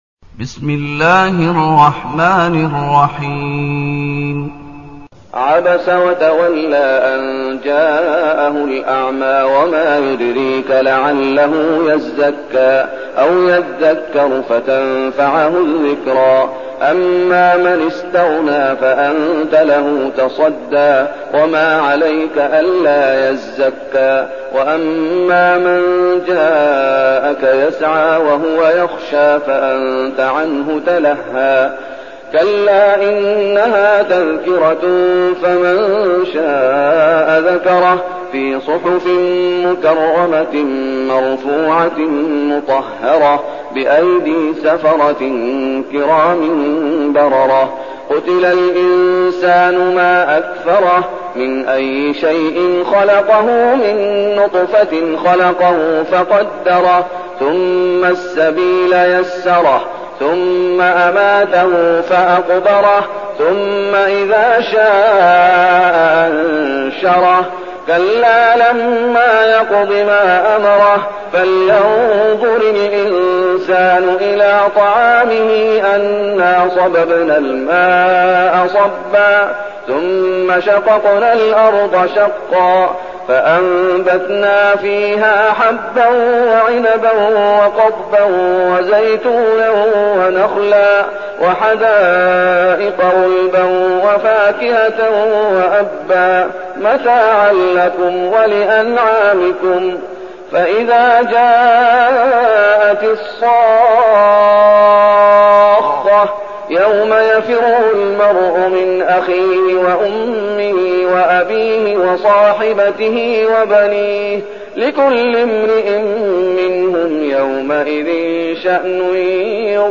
المكان: المسجد النبوي الشيخ: فضيلة الشيخ محمد أيوب فضيلة الشيخ محمد أيوب عبس